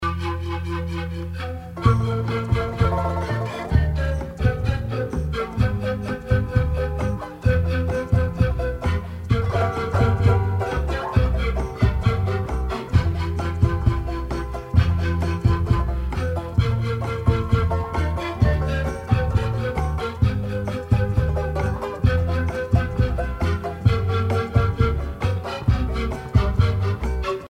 danse : boléro